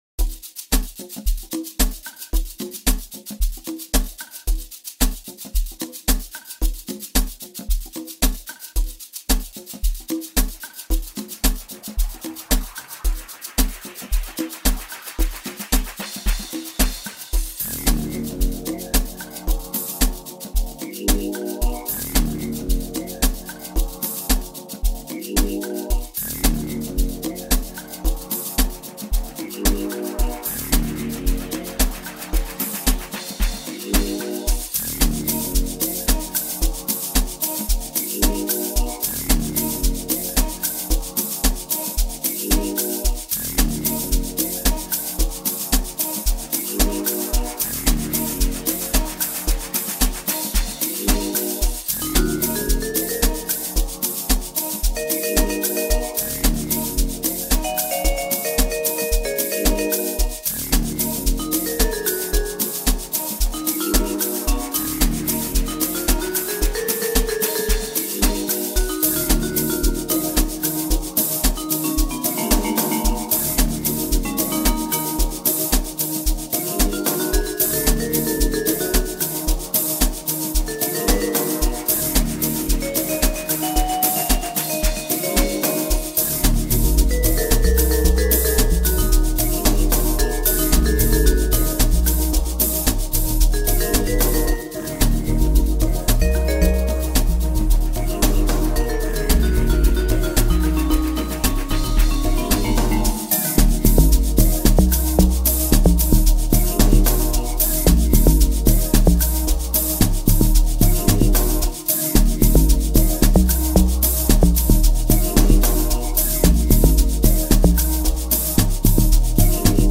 private school piano flair
recent soulful elements